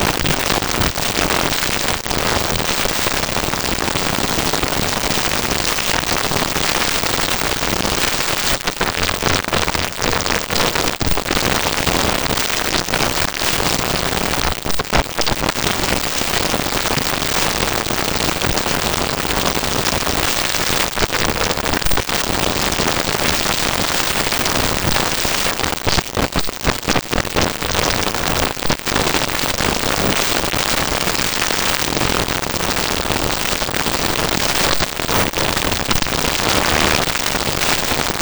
PRÓBKA GŁOSU ENG
probka-glosu-eng.wav